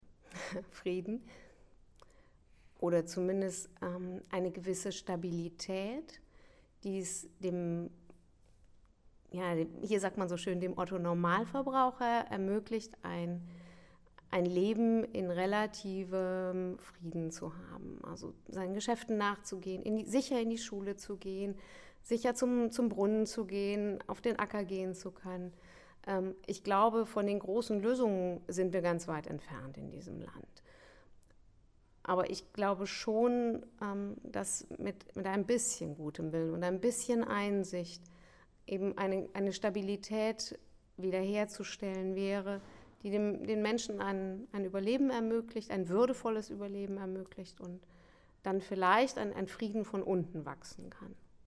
Audio Ausschnitt aus dem Film "Willi in Indien"